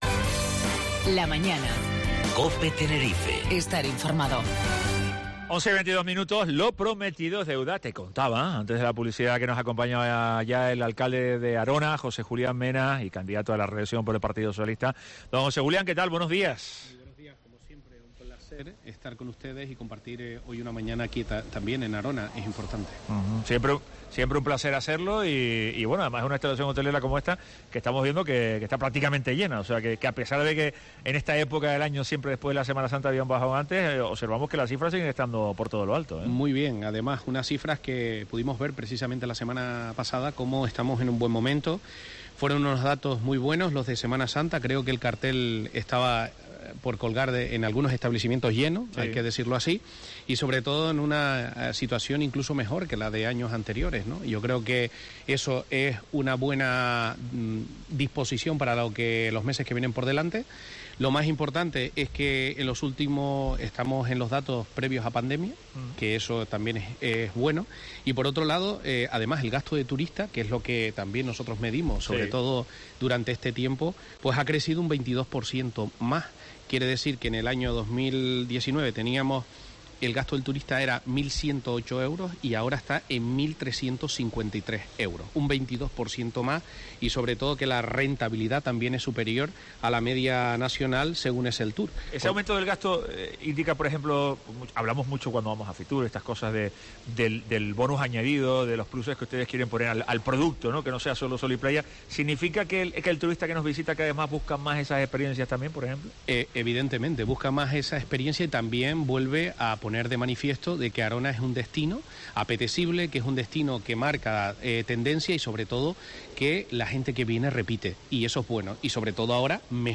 José Julián Mena, alcalde de Arona, en La Mañana en Tenerife